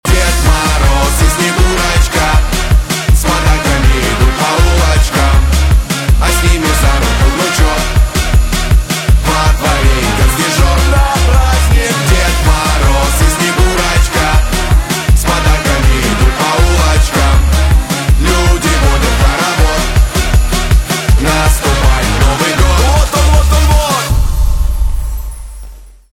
поп
веселые , барабаны